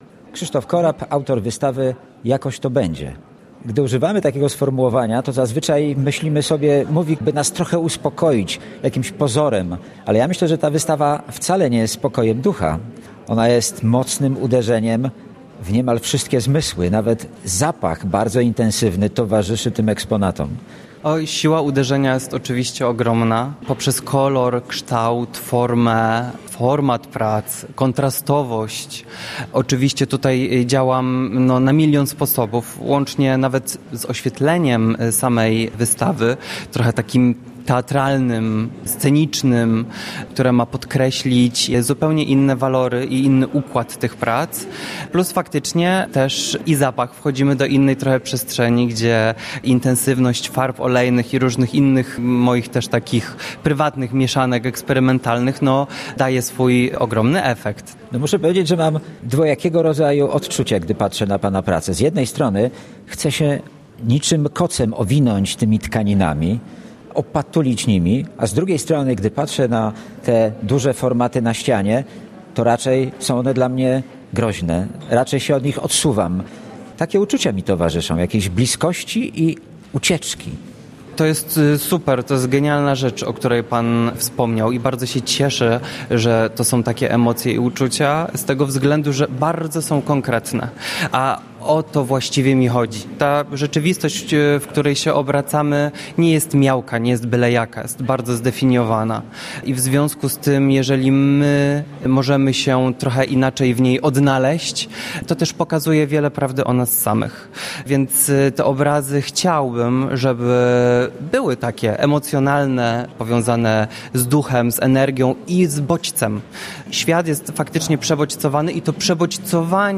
Rozmowa w BWA